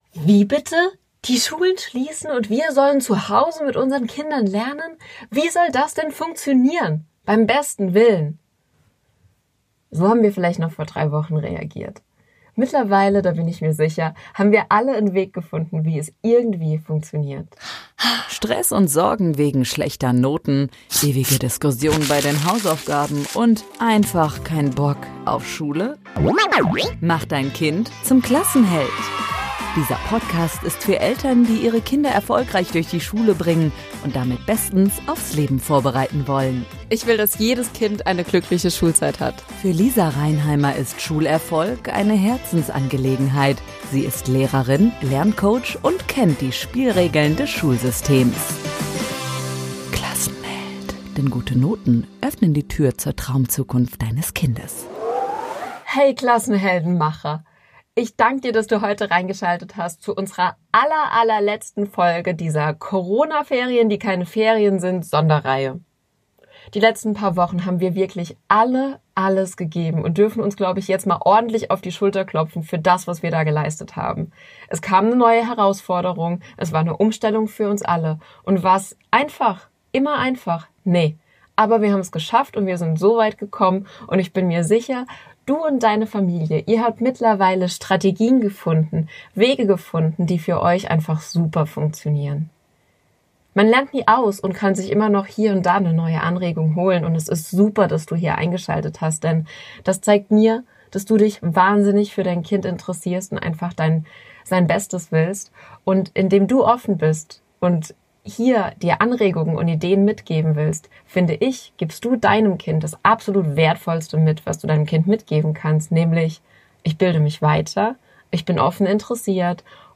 Leistungsdruck und Schulstress entkommen - Interview